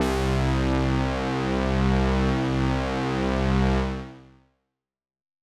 Synth Pad C2.wav